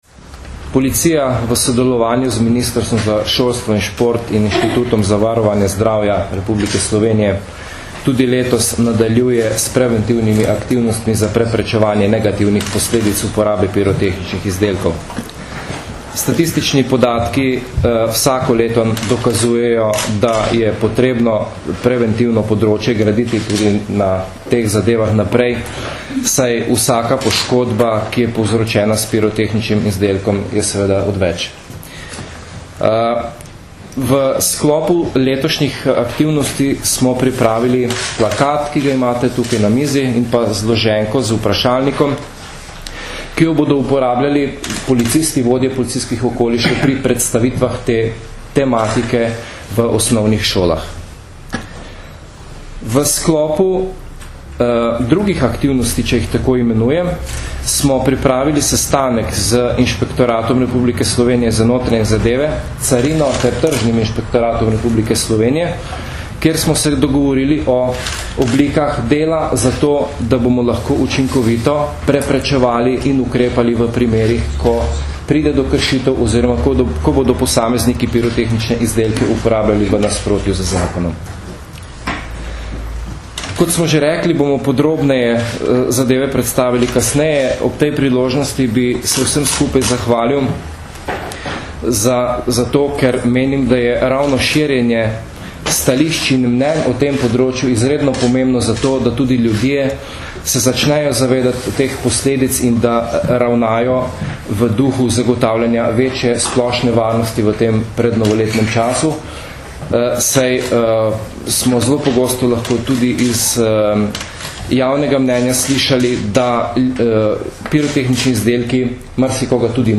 Na današnji novinarski konferenci so predstavniki policije in inšpektorata Republike Slovenije za notranje zadeve na kratko predstavili novosti, ki jih prinaša novi Zakon o eksplozivih in pirotehničnih izdelkih.